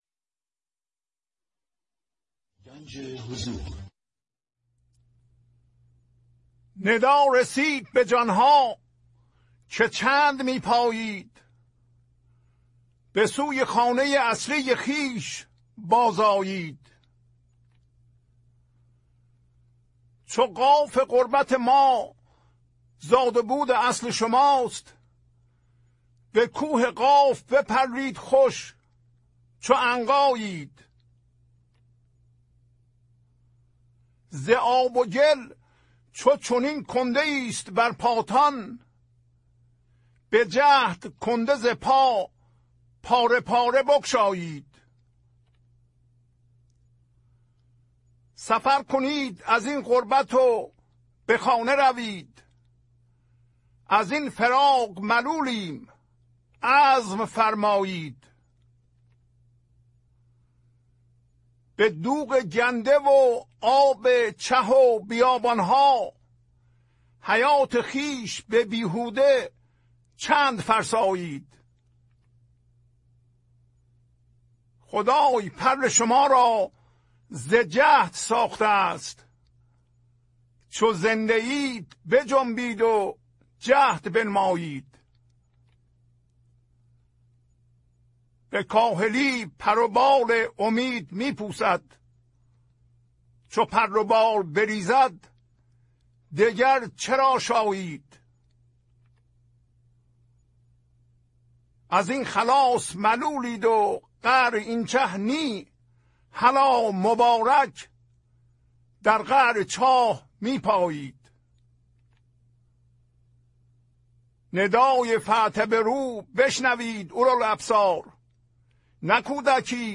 خوانش تمام ابیات این برنامه - فایل صوتی
1015-Poems-Voice.mp3